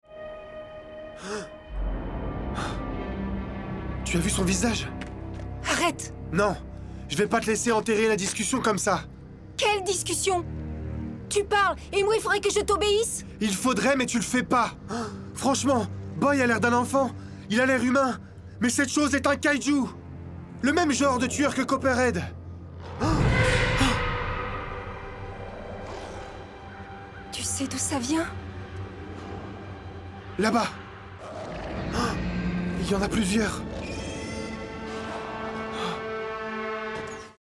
Voix off
35 - 50 ans - Mezzo-soprano